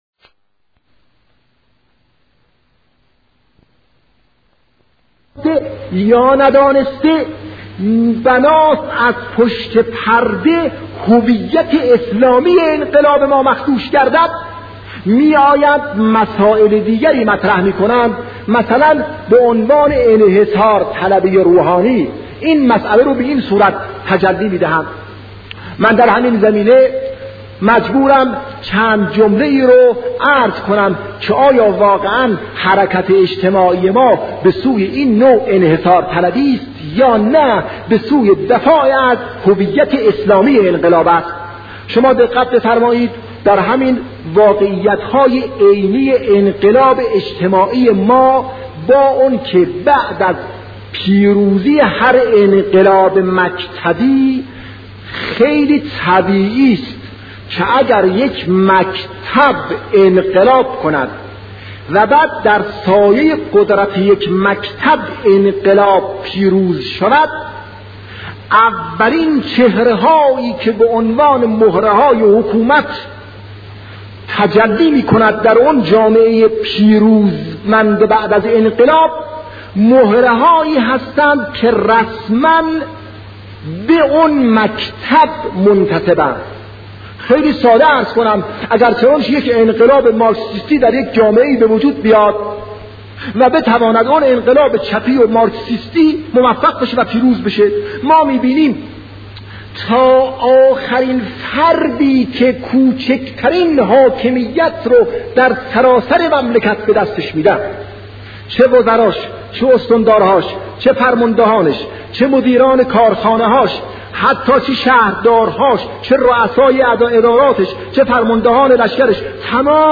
انقلاب و هویت اسلامی، از زبان شهید محمدجواد باهنر
بیانات بزرگان